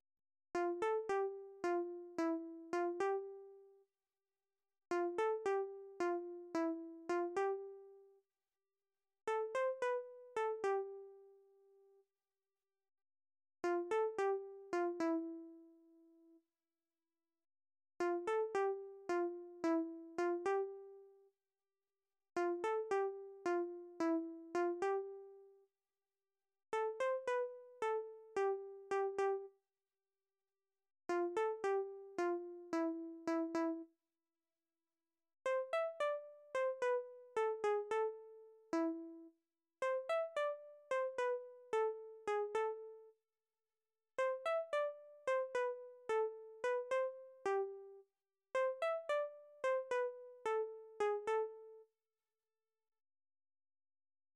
Műfaj magyar könnyűzenei dal
Hangfaj moll
A kotta hangneme Á moll